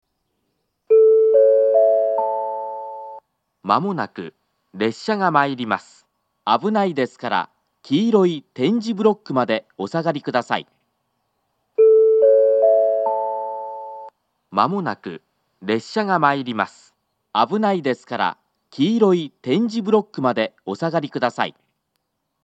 ２０２５年６月には放送装置が更新されているのが確認され、「黄色い点字ブロックまで」と言う放送になっています。
２番線接近放送 交換がある場合のみ使用するホームです。